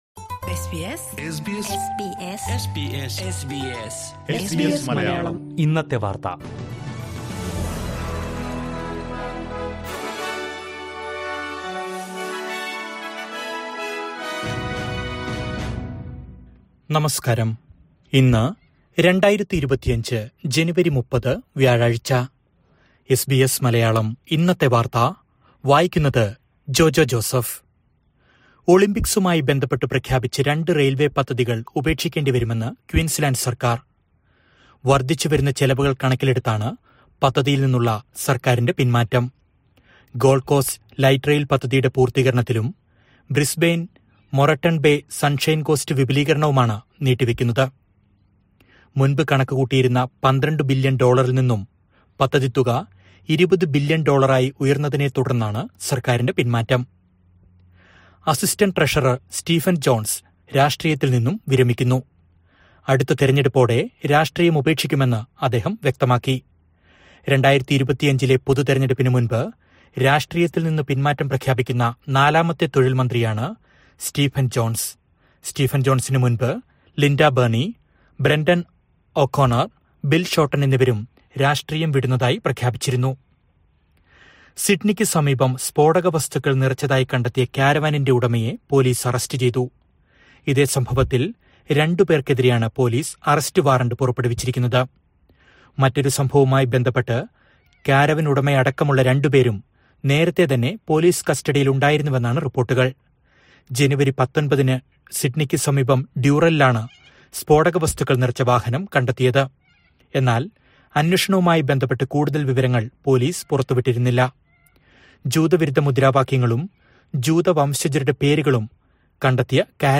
2025 ജനുവരി 30ലെ ഓസ്‌ട്രേലിയയിലെ ഏറ്റവും പ്രധാന വാര്‍ത്തകള്‍ കേള്‍ക്കാം...